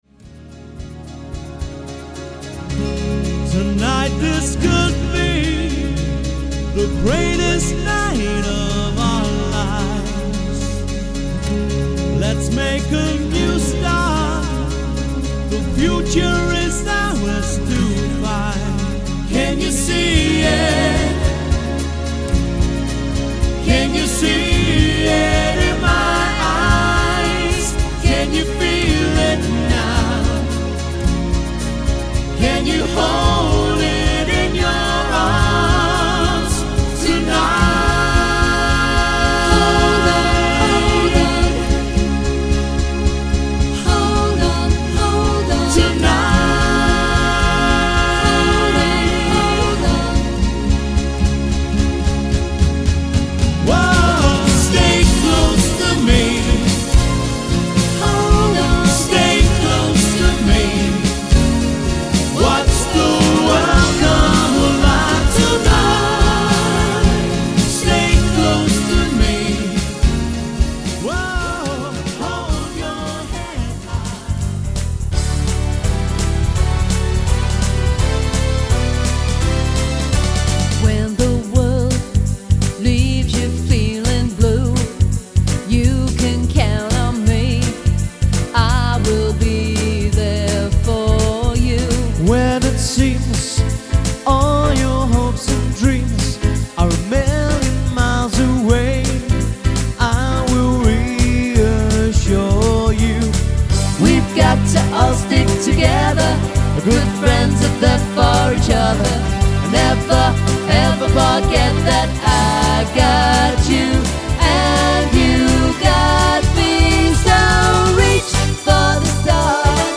joining their vocal & keyboard talents
duo